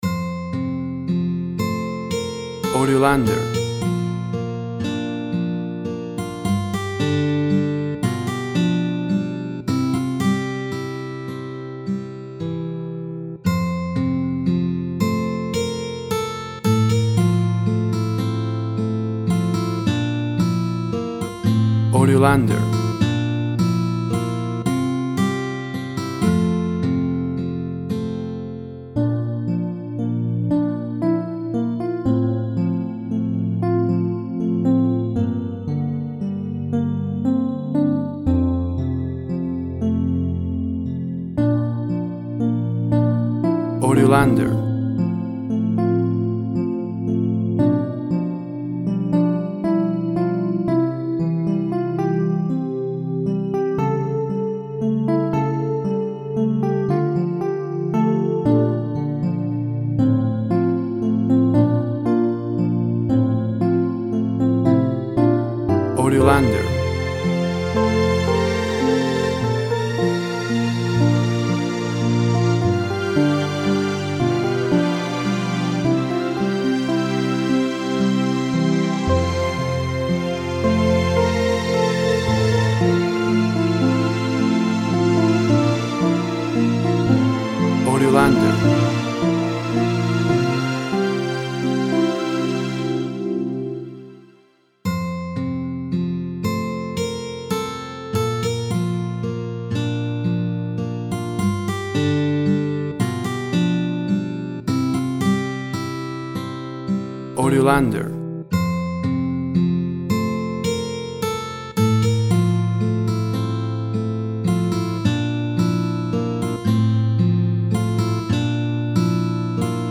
Tempo (BPM) 68